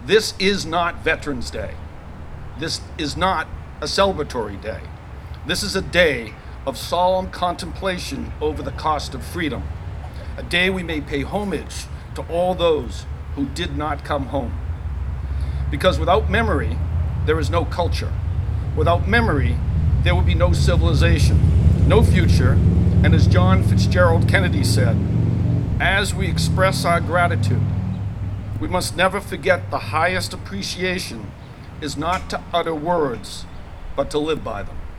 Hundreds of people lined the Veterans Memorial Park for the annual observance.
Jim Kilcoyne, chair of the Select Board, says people often lose sight of the true meaning of Memorial Day.